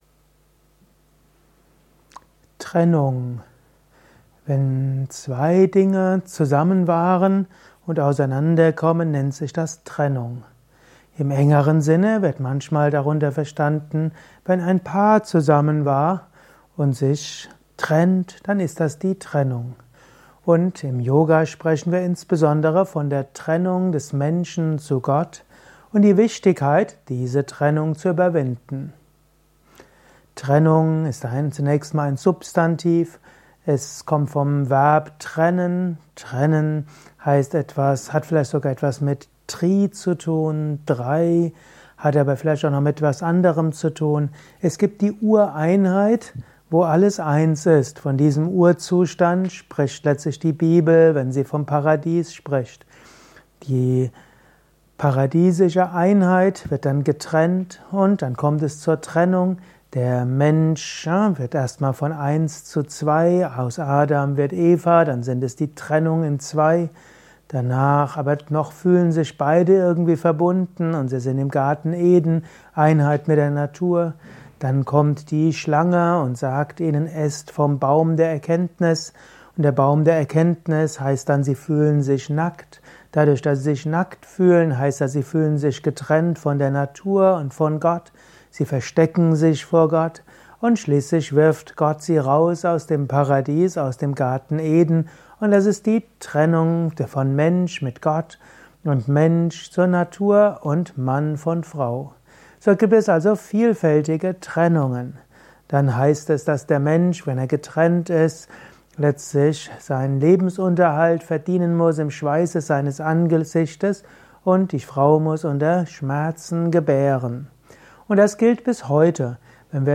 Dieser Vortrag ist Teil des Liebe Podcasts